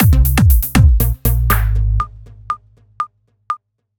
120 BPM Beat Loops Download